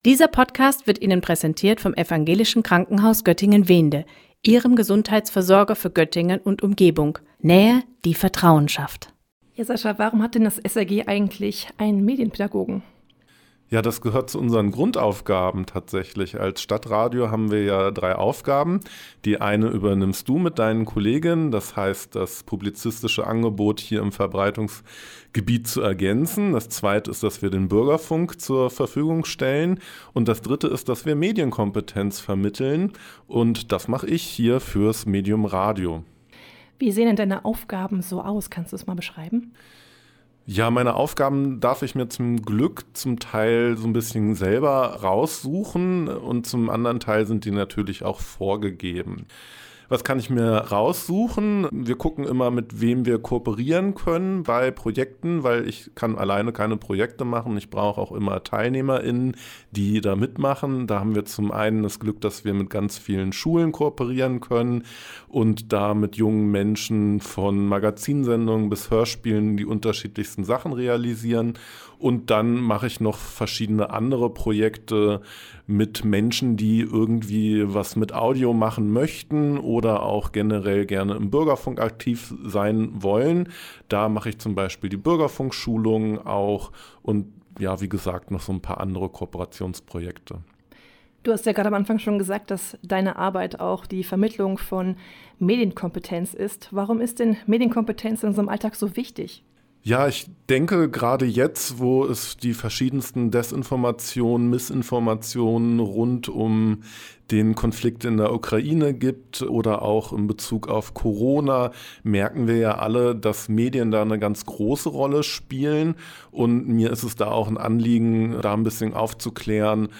Beiträge > Die Medienpädagogik im StadtRadio Göttingen stellt sich vor – Gespräch